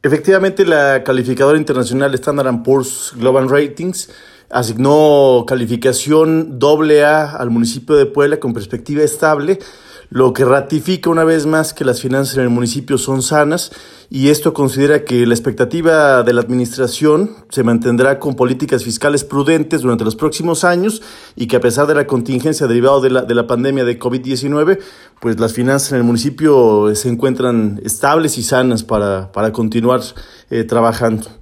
Tesorero.m4a